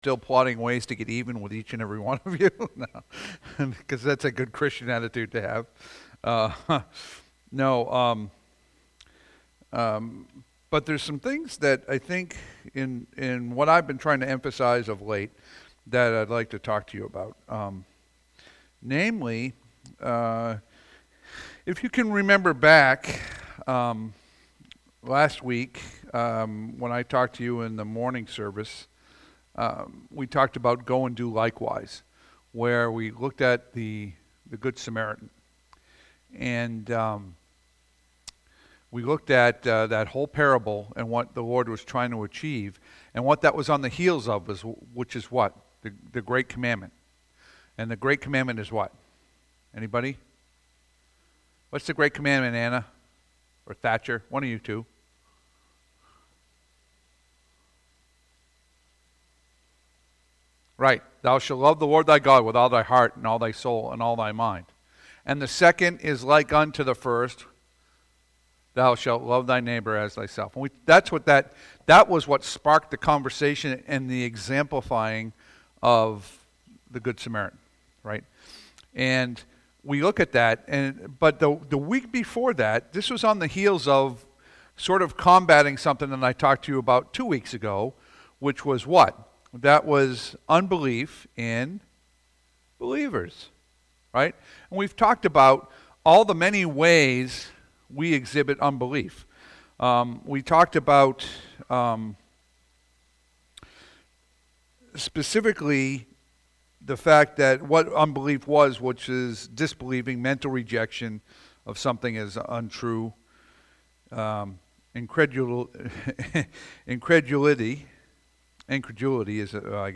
Passage: Luke 12:4-7, 13-21 Service Type: Sunday PM « January 11